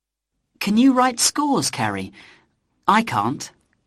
Notice the distinction in vowel quality and stress between the positive /kən/and the negative /kɑːnt/. Here the /t/ is pronounced, which is only normal, being at the end of the sentence.